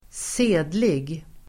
Ladda ner uttalet
Uttal: [²s'e:dlig]
sedlig.mp3